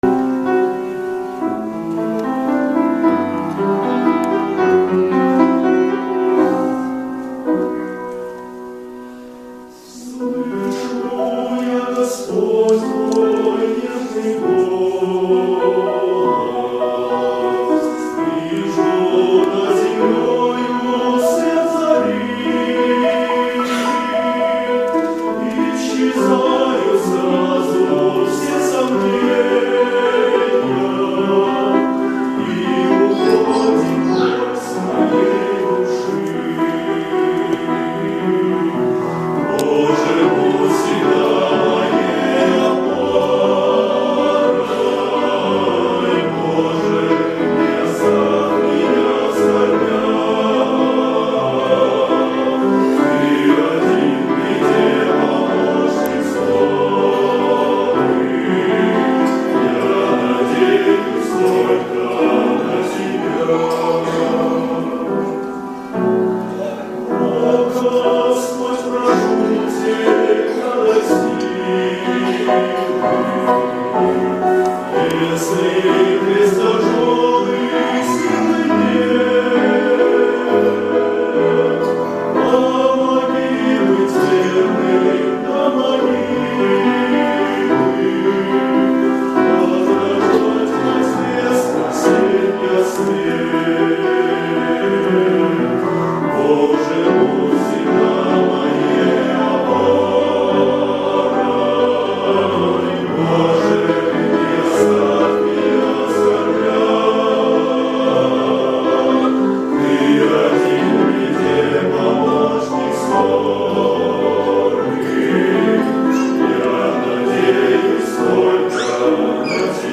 песня
98 просмотров 121 прослушиваний 6 скачиваний BPM: 92